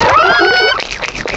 Cri de Tritosor dans Pokémon Diamant et Perle.